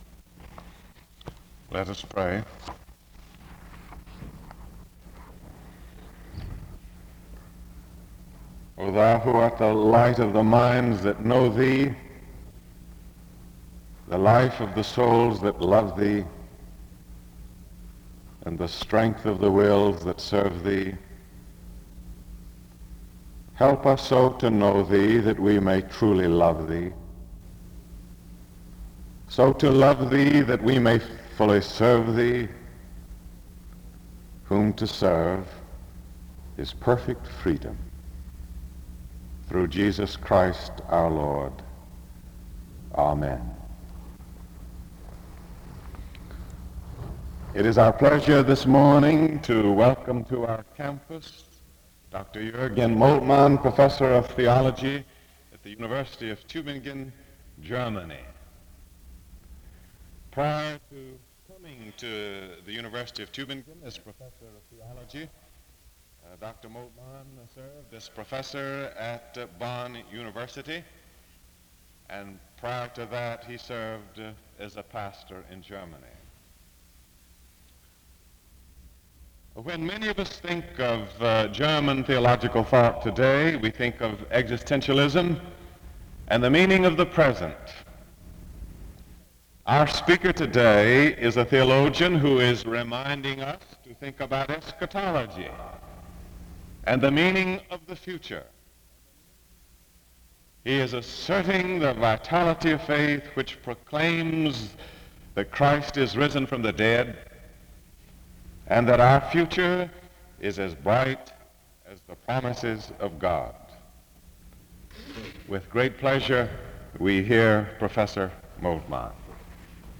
SEBTS Chapel - Eugene Moltmann March 14, 1968
Eugene Moltmann was Professor of Theology at the University of Tubingen in Germany. The service starts with a word of prayer from 0:00-0:43. An introduction to the speaker is given from 0:47-2:00.
Dr. Moltmann speaks from 2:09-58:20. Moltmann encourages the students to view the resurrection of Jesus and be greatly expectant on his return and how that should affect how believers live today. The service closes in prayer from 58:28-59:03.